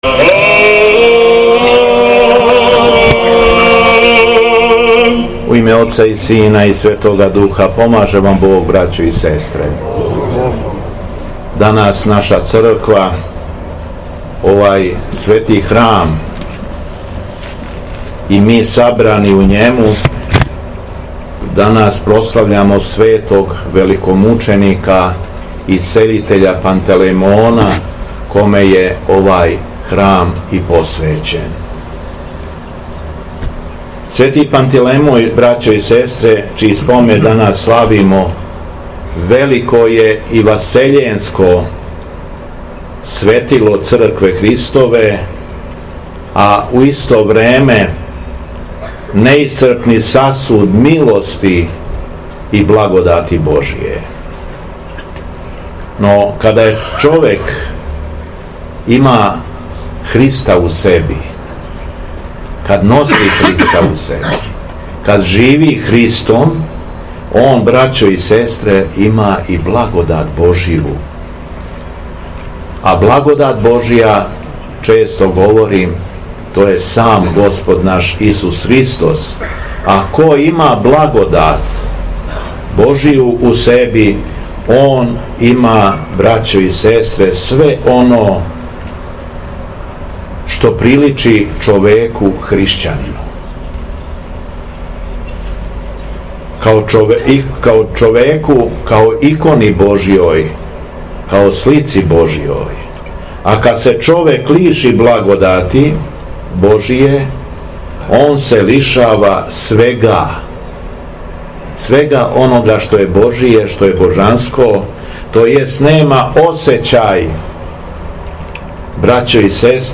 Беседа Његовог Високопреосвештенства Митрополита шумадијског г. Јована
Након прочитаног јеванђелског зачала, Митрополит се обратио вернима богонадахнутом беседом рекавши: